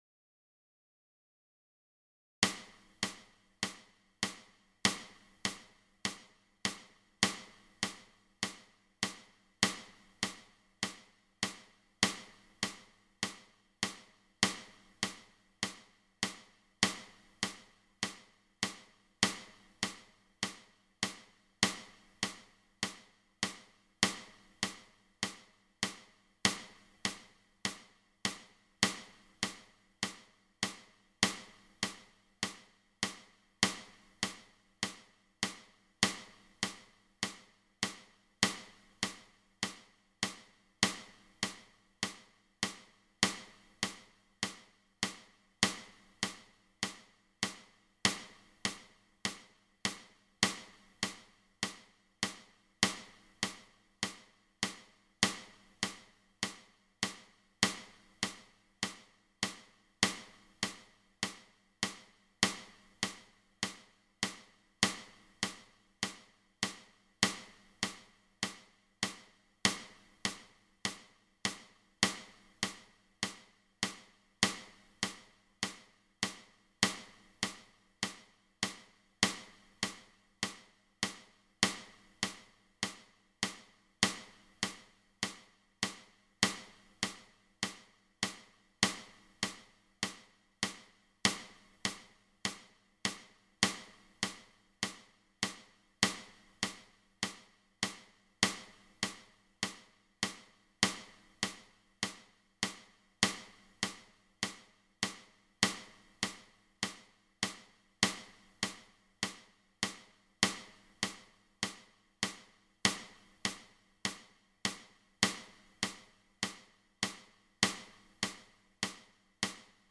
Dies sind ca. 5 Minuten lange Audiodateien im 4/4 Takt. Der erste Takt (die 1) ist immer etwas lauter als die drei darauffolgenden Takte: 1, 2, 3, 4 (dann wiederholt sich der Takt immer wieder).
Clicktrack mit 100 BPM
ClicktrackBPM100.mp3